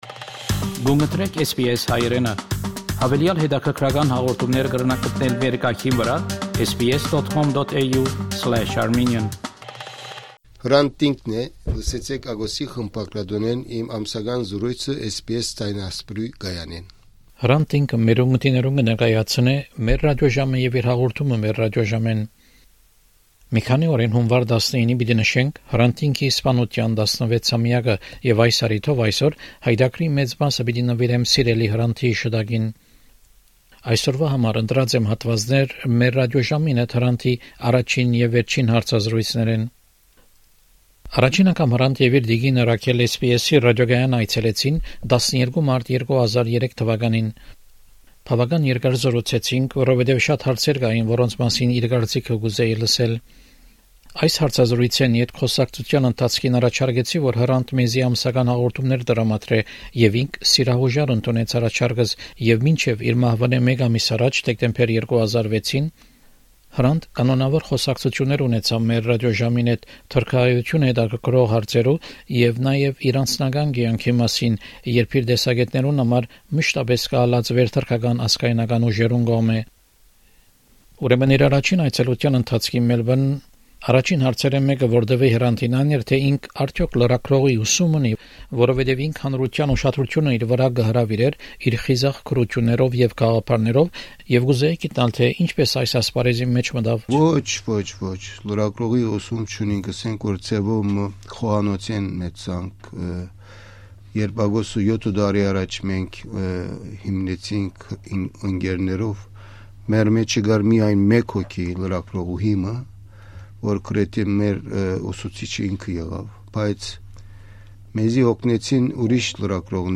The program is dedicated to Hrant Dink. It includes segments of an interview with SBS Armenian during his first visit to Australia in 2003 and a segment from his last interview in December 2006, a month before his assassination.